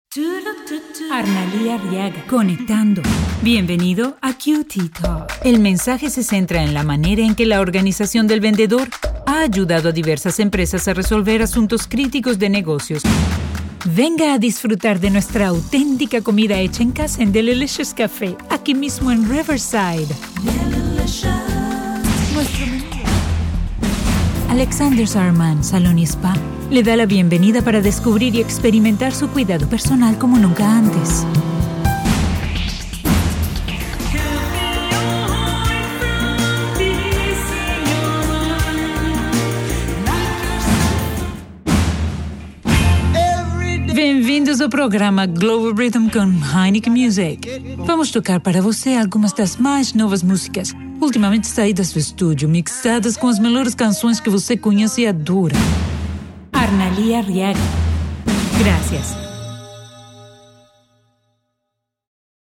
My accent is Neutral / Latino / Mexican / Venezuelan.
I have a deep, warm voice with a captivating very persuasive warm tone that gathers attention.
Sprechprobe: Industrie (Muttersprache):